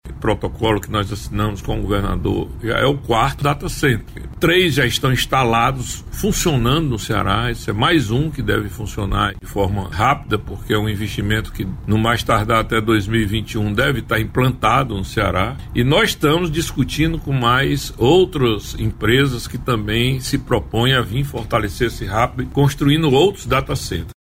O secretário do Desenvolvimento Econômico e Trabalho (Sedet), Maia Júnior, fala sobre o protocolo para a instalação de mais um centro de processamento de dados no Ceará.